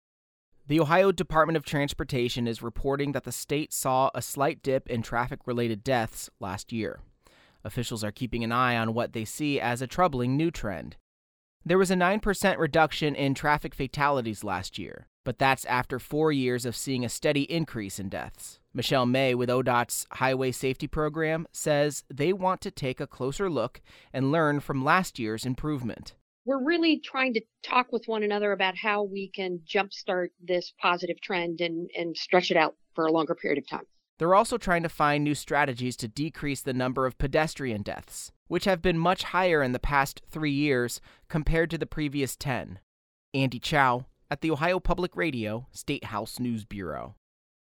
traffic_deaths_report_web.mp3